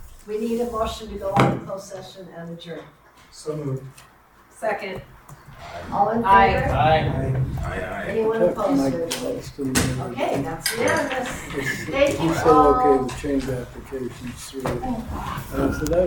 Midway Community Center
Meeting of the Midway City Council.